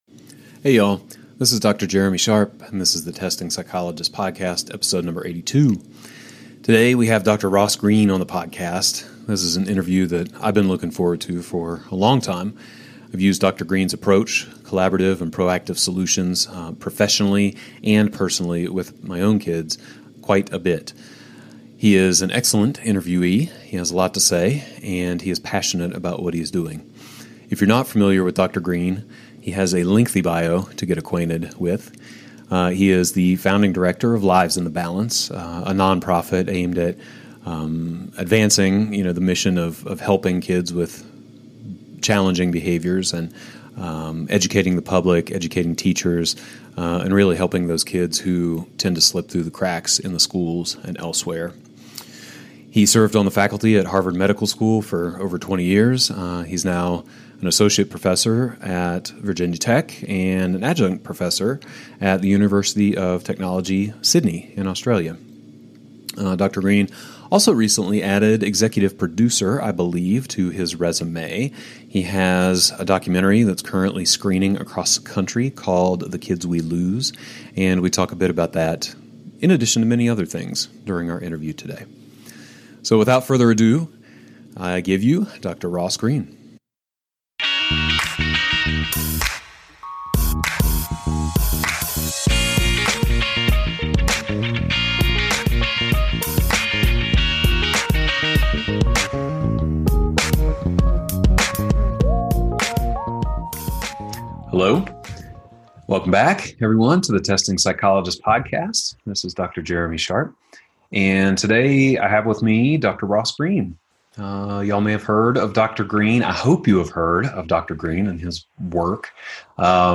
Recorded audio/video with transcript.